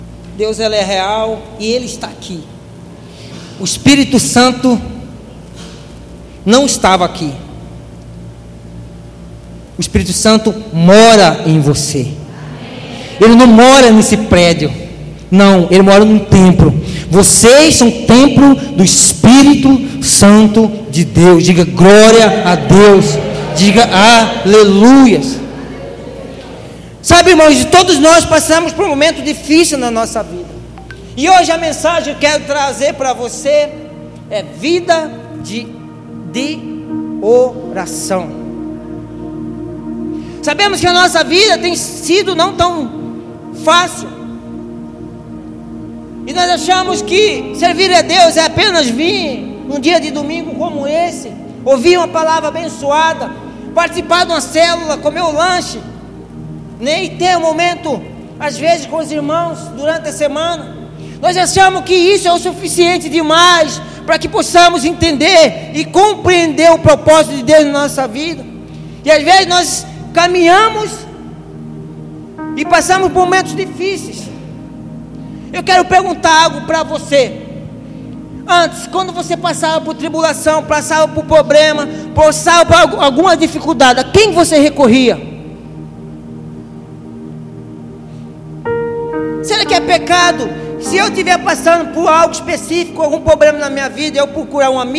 Culto de Celebração